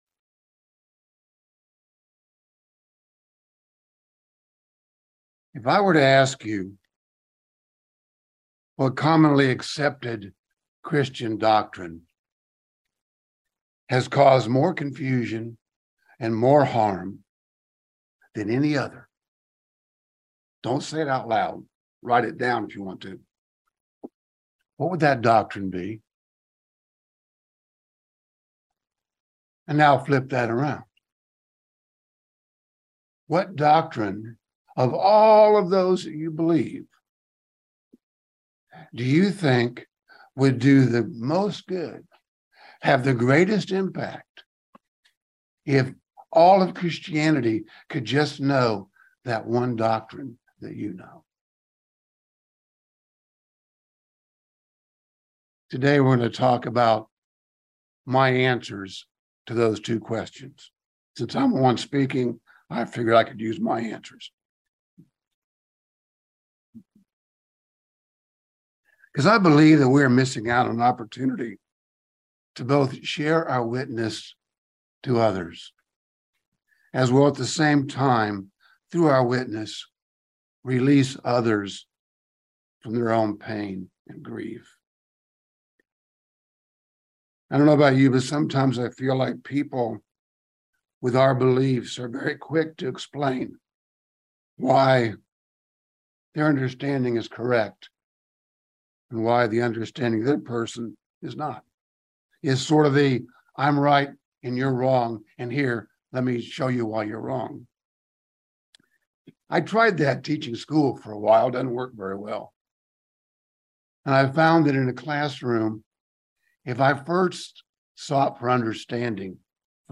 Sermons
Given in London, KY